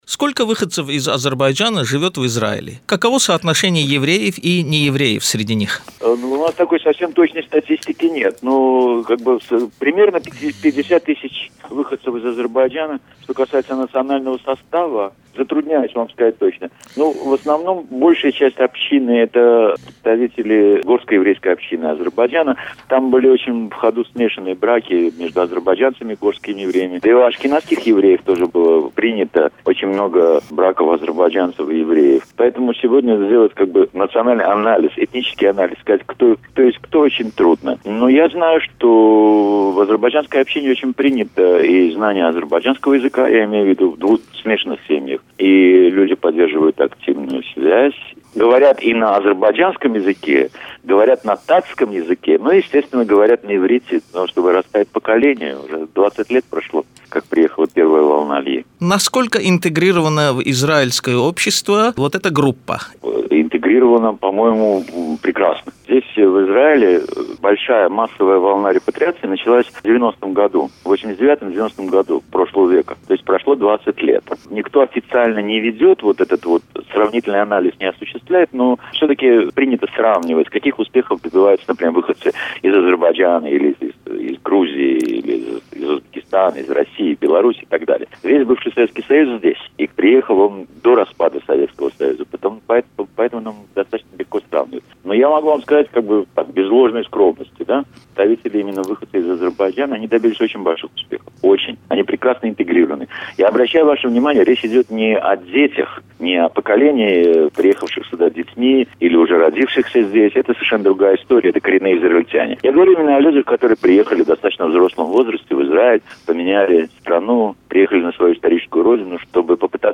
Интервью РадиоАзадлыг с президентом международной ассоциации "Израиль-Азербайджан" Иосифом Шагалом